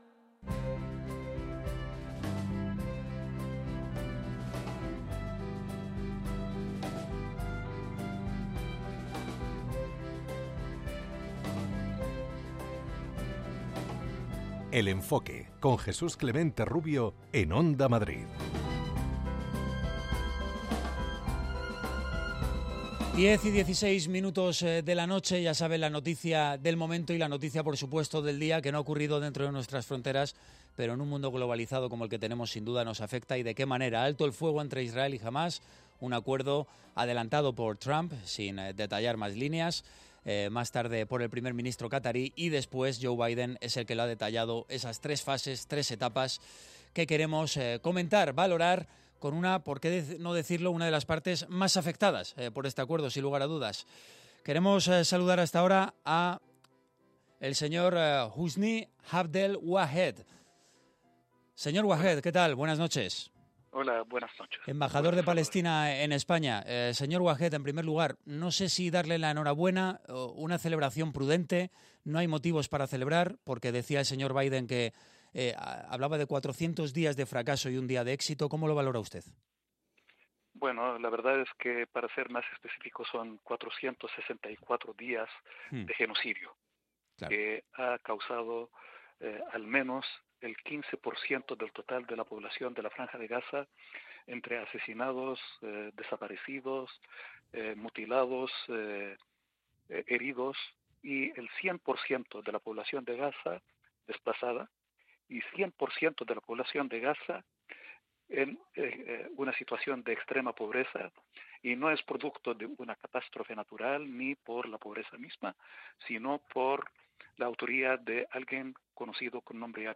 ha entrevistado al embajador de la Autoridad Nacional Palestina, Husni Abdel Wahed, quien ha valorado el anuncio del alto el fuego en Gaza.